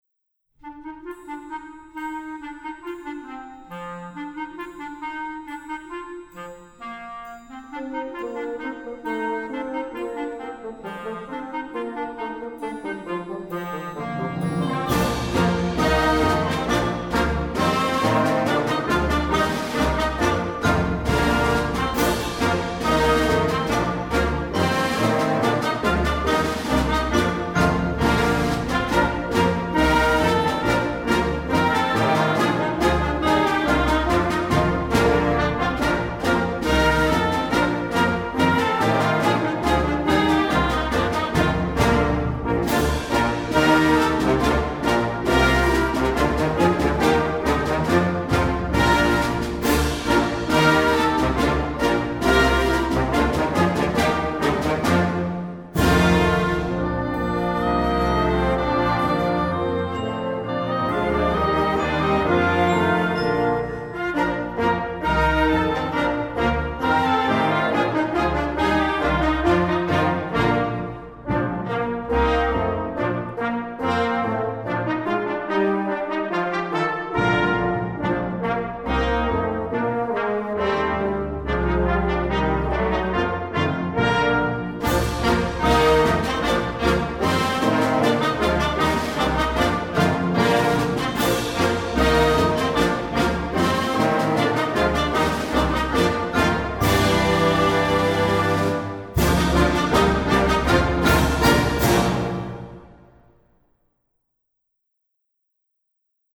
British Folk Song
Concert Band